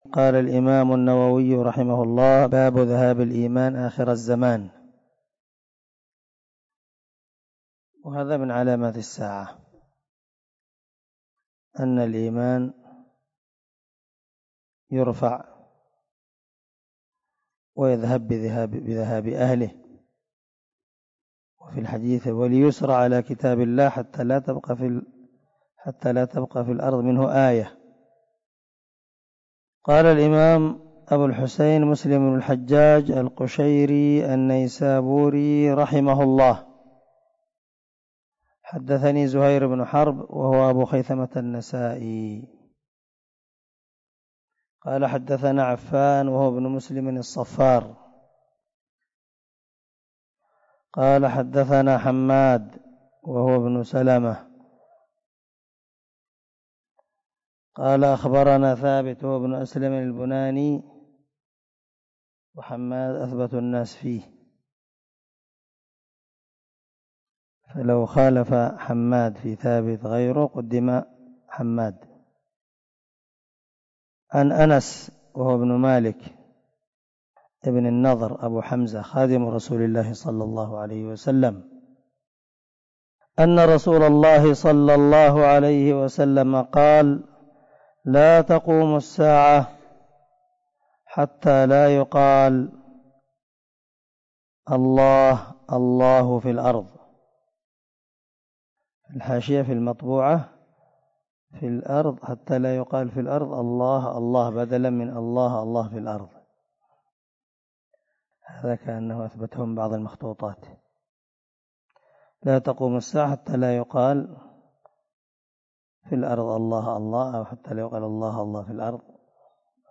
107الدرس 97 تفسير آية ( 218- 219 ) من سورة البقرة من تفسير القران الكريم مع قراءة لتفسير السعدي